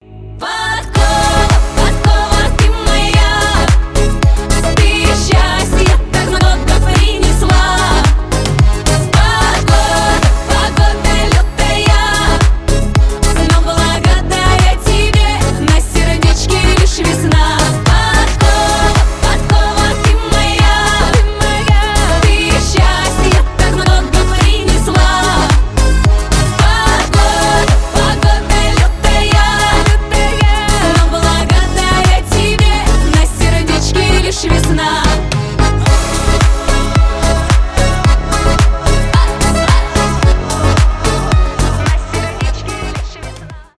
поп , фолк